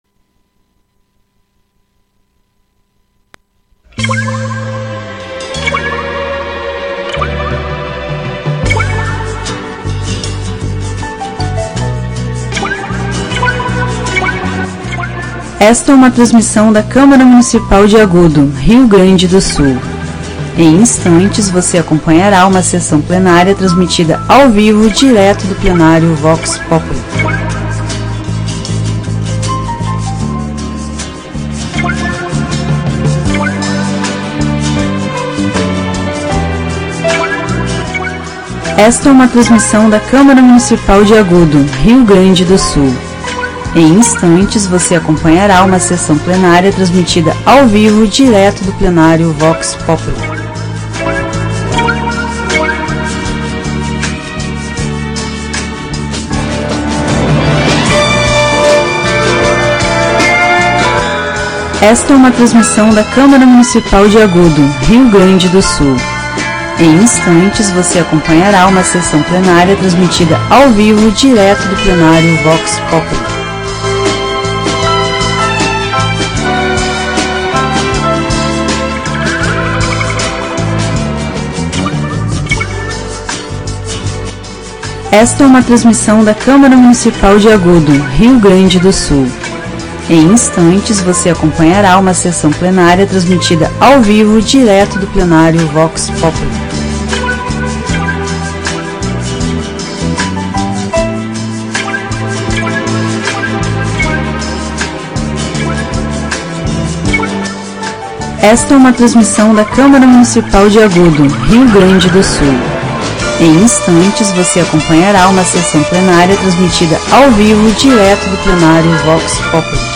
Áudio da 46ª Sessão Plenária Ordinária da 17ª Legislatura, de 09 de março de 2026